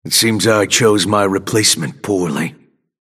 Grey Talon voice line (kill Shiv) - It seems I chose my replacement poorly.